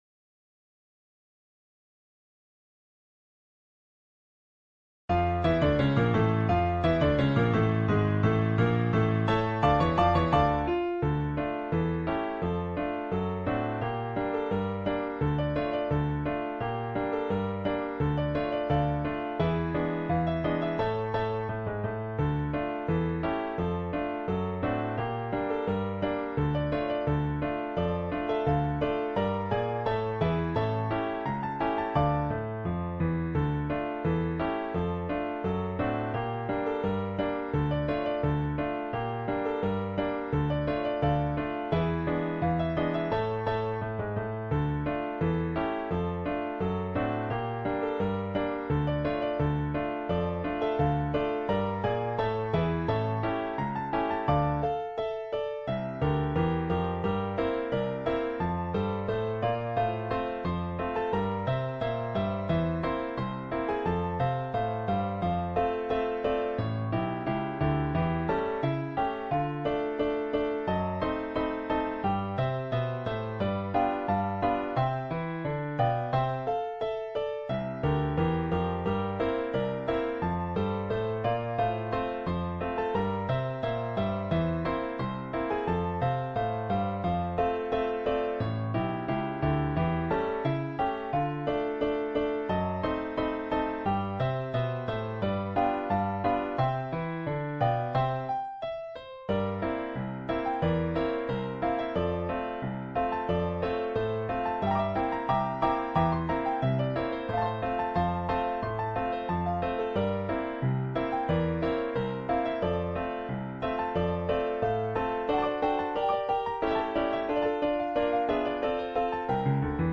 Musik von Scott Joplin
Die Partitur enthält Hinweise auf den Unfall, beispielsweise: »The noise of the trains while running at the rate of sixty miles per hour« oder »Whistle before the collision«.